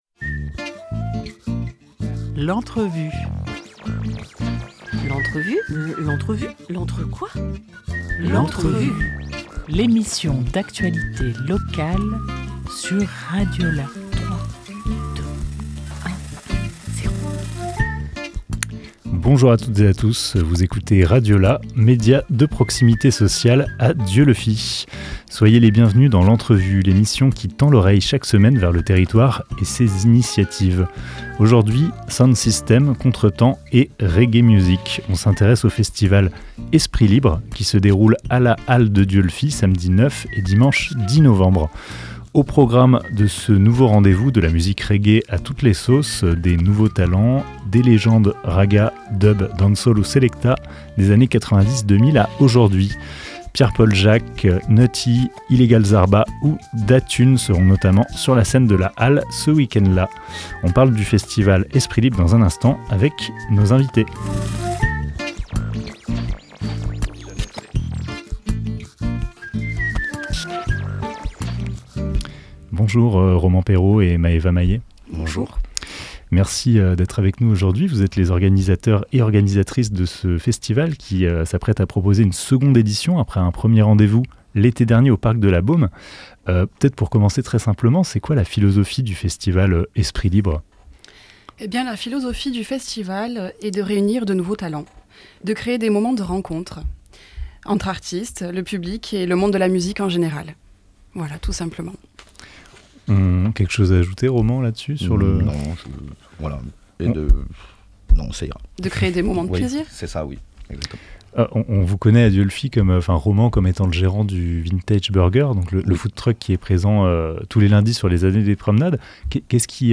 15 octobre 2024 11:32 | Interview